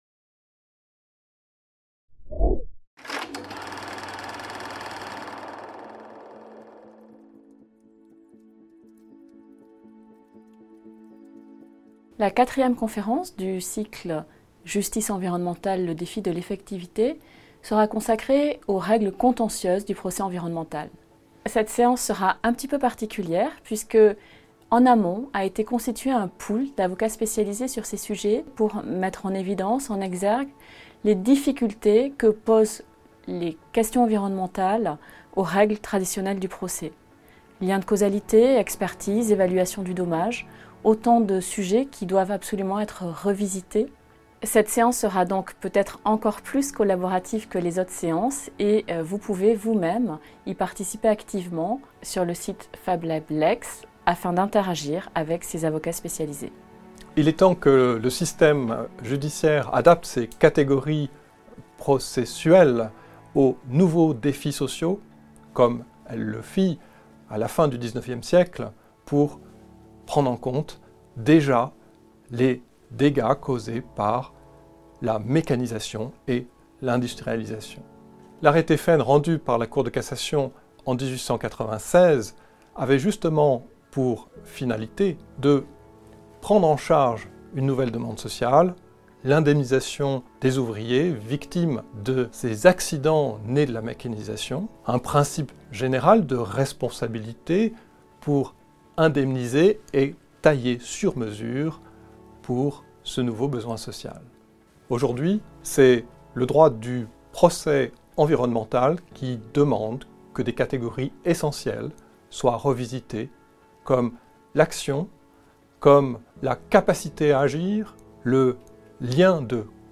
Justice environnementale, le défi de l'effectivité - Conférence 4 | Canal U
Programme 2020/2021 du cycle bi-annuel de conférences à la Cour de cassation